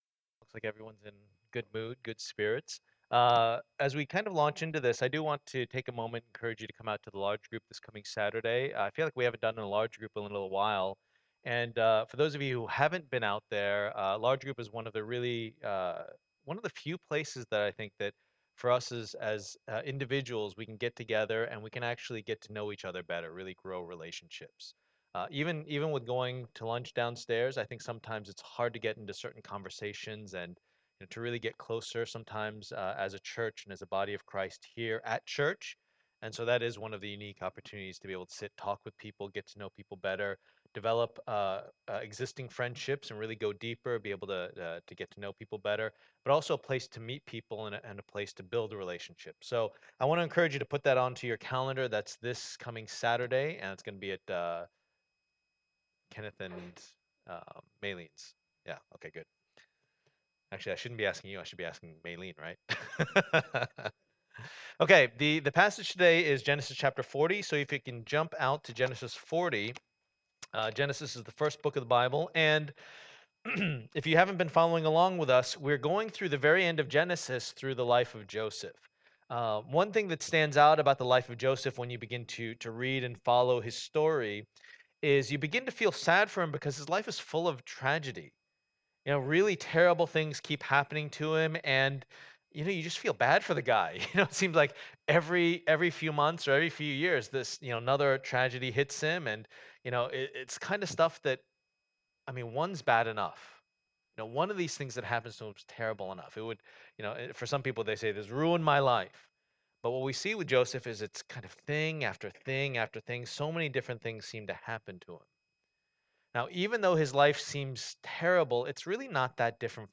Passage: Genesis 40:1-23 Service Type: Lord's Day